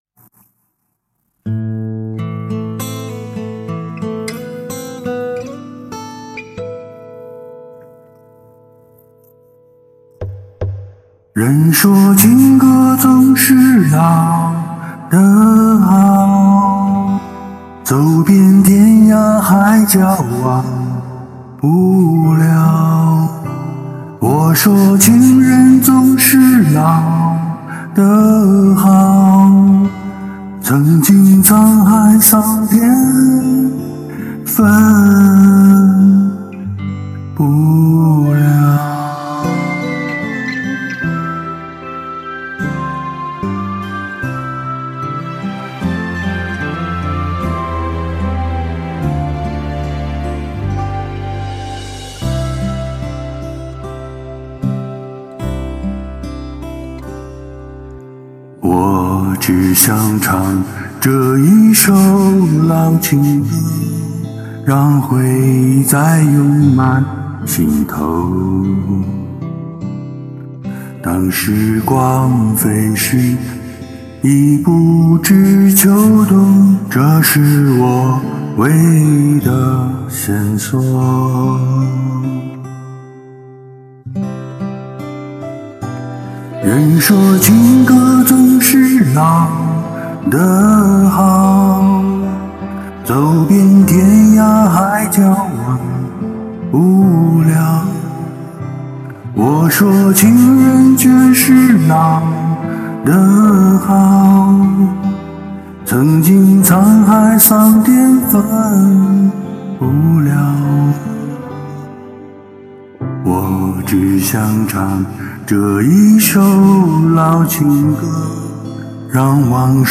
从悠扬舒缓的诉说，到情感浓烈时的迸发，层次分明，让听众仿佛置身于那段难以忘怀的岁月之中，跟着歌声一同回味、一同感动。